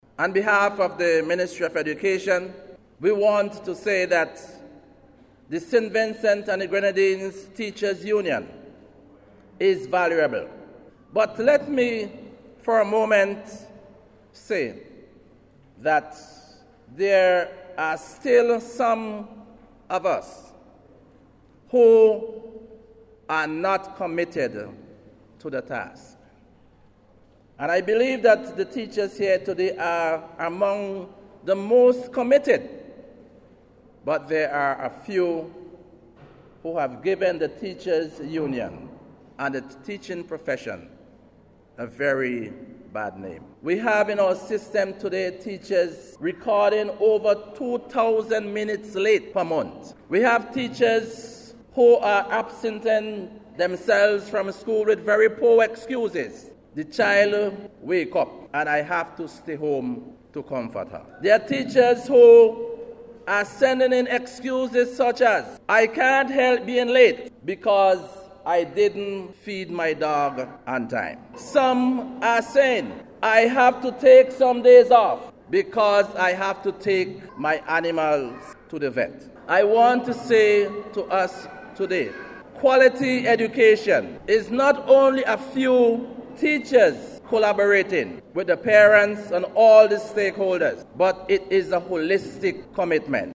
as he delivered remarks at the opening of the 18th Biennial Convention of the St. Vincent and the Grenadines Teachers Union last week.